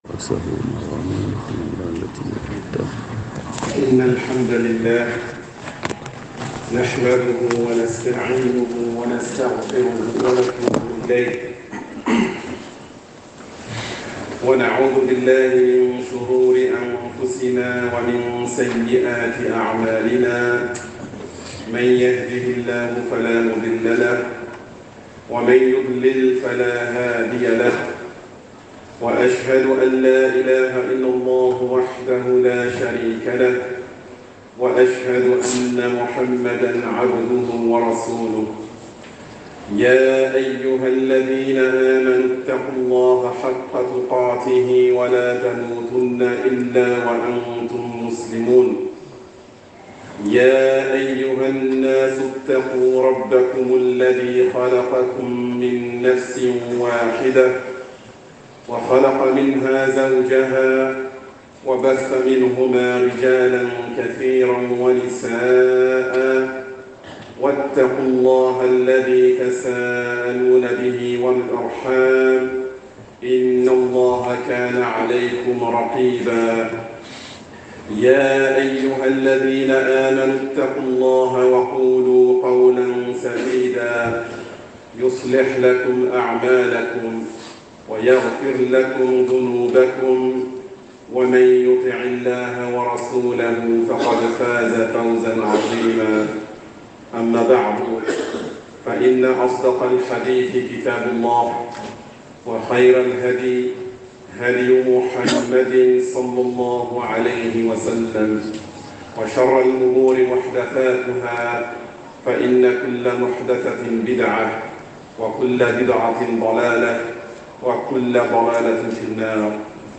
“Grande mosquée de Pantin” Vendredi
Prêches